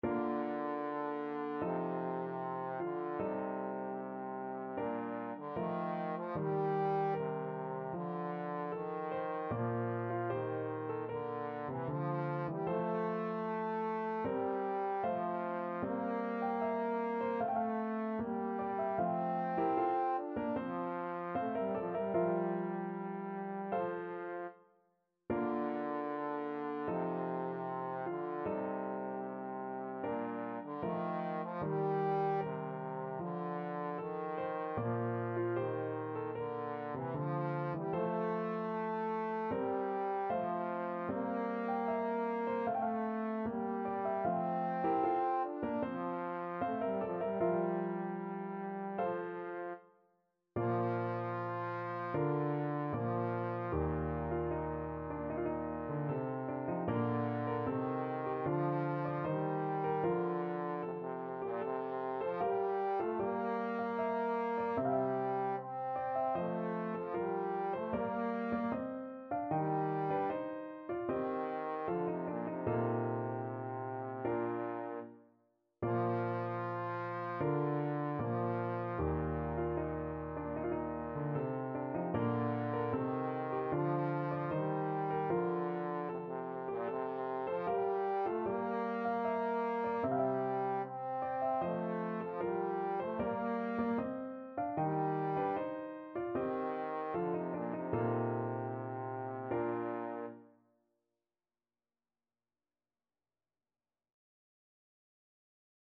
4/4 (View more 4/4 Music)
Andante =76
Classical (View more Classical Trombone Music)